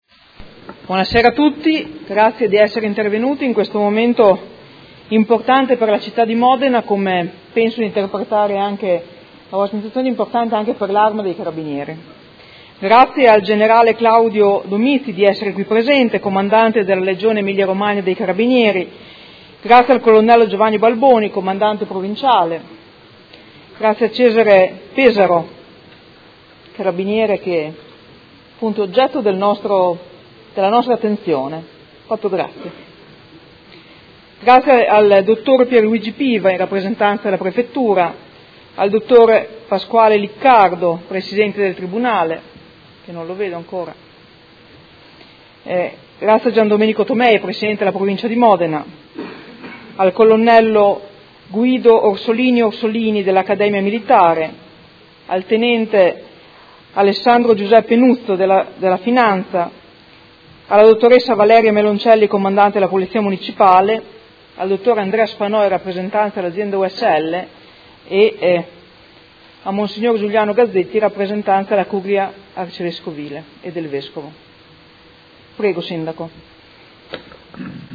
Seduta del 24/01/2019.